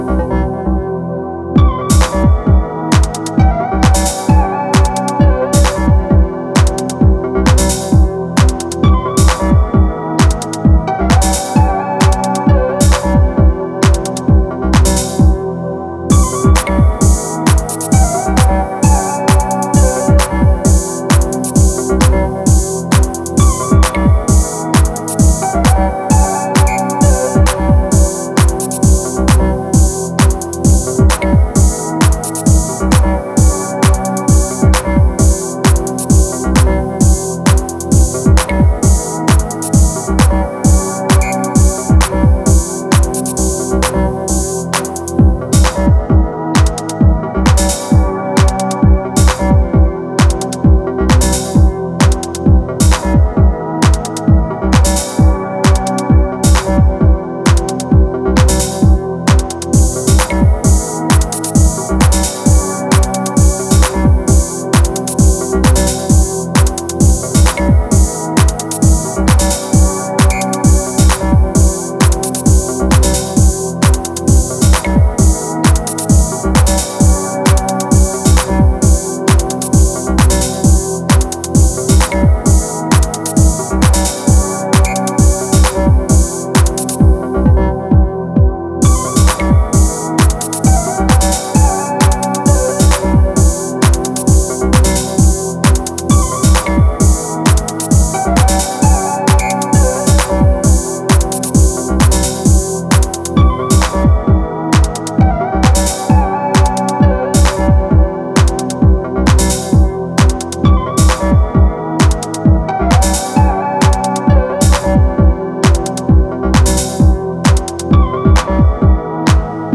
one of the most creative Techno producers in recent years